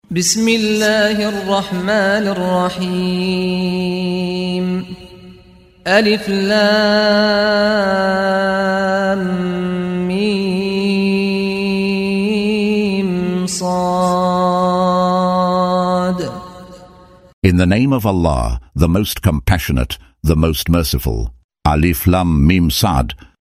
Audio version of Surah Al-A'raf ( The Heights ) in English, split into verses, preceded by the recitation of the reciter: Saad Al-Ghamdi.